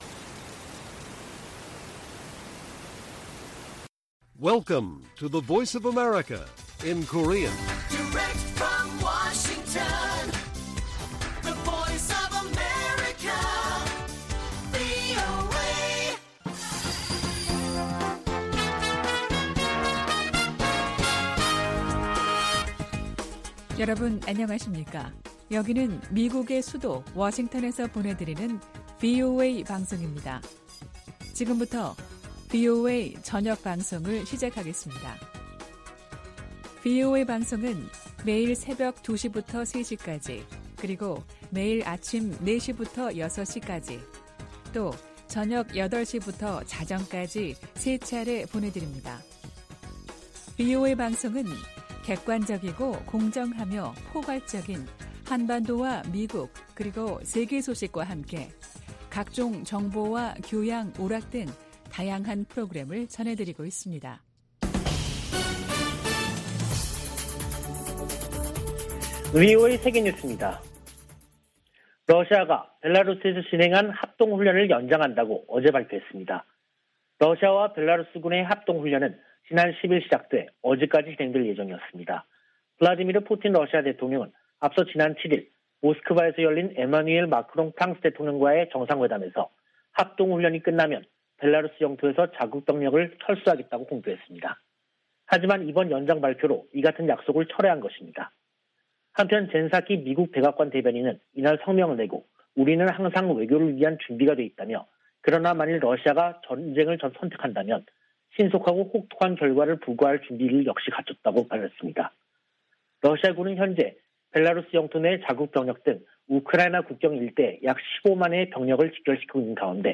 VOA 한국어 간판 뉴스 프로그램 '뉴스 투데이', 2022년 2월 21일 1부 방송입니다. 존 볼튼 전 백악관 국가안보보좌관은 북한 정권 교체 가능성과 핵 프로그램에 대한 무력 사용이 배제돼선 안 된다고 주장했습니다. 북한 비핵화 문제는 미-북 간 최고위급 논의가 필요하다고 마이크 폼페오 전 미 국무장관이 말했습니다. 한국의 북한 출신 국회의원들이 유엔 북한인권특별보고관에게 북한 반인도 범죄자들에 고소·고발 지원을 요청했습니다.